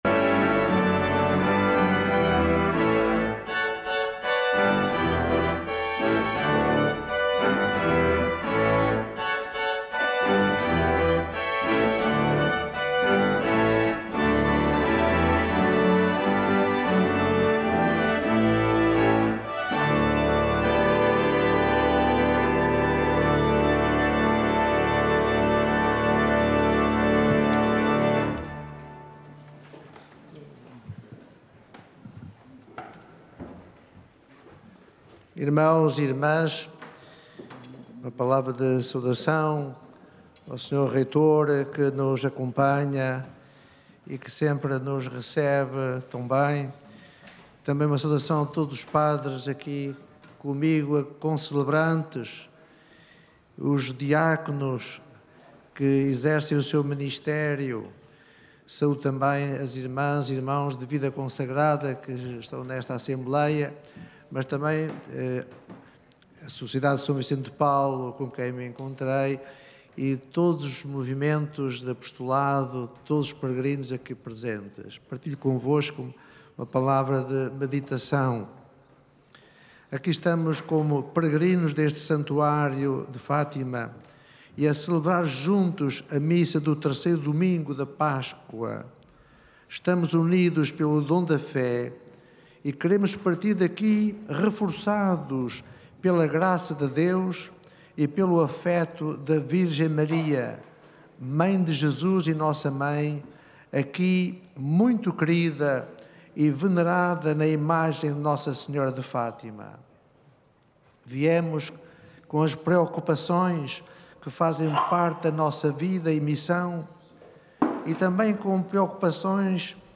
Uma transformação interior e uma ação social inspirada pela Ressurreição de Cristo foi o que pediu D. José Traquina, bispo de Santarém, na homilia da missa deste III Domingo da Páscoa, celebrada esta manhã no Recinto de Oração do Santuário de Fátima.